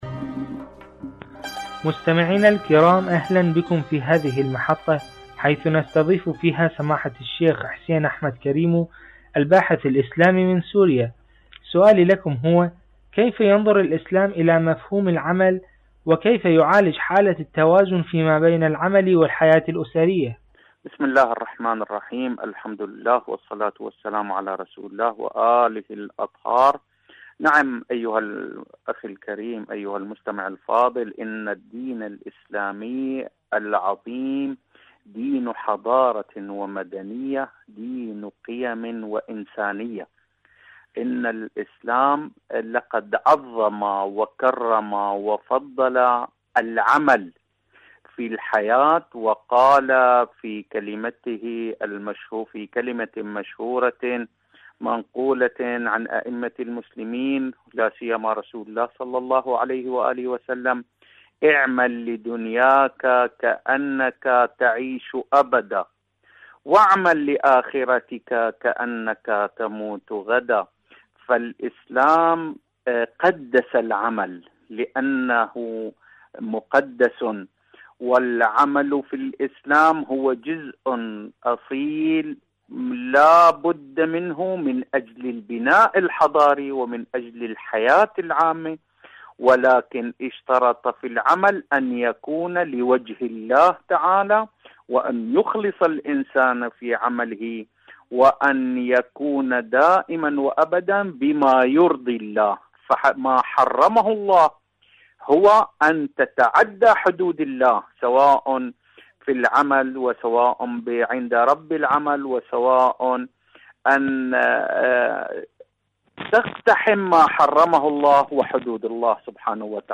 مقابلة